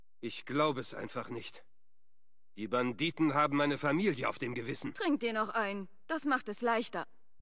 FOBOS-Dialog-Carbon-Bürger-006.ogg